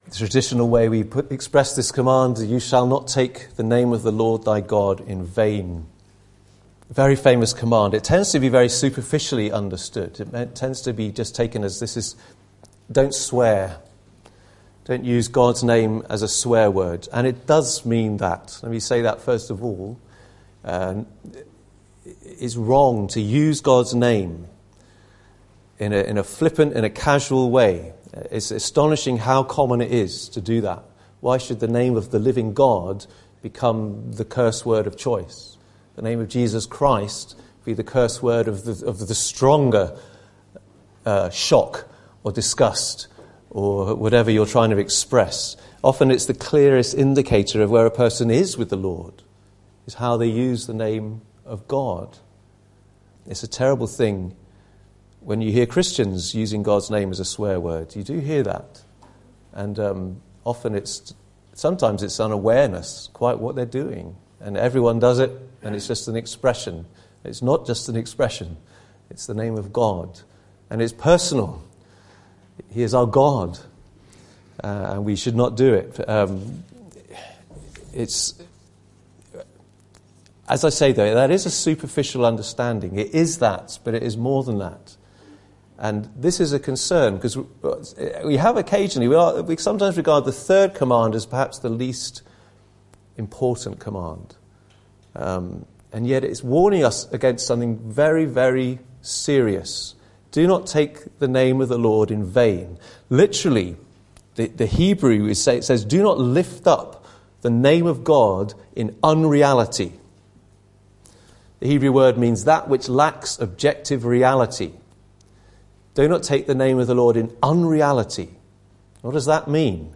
Matthew 7:21-23 Service Type: Sunday Morning « Is Image Everything?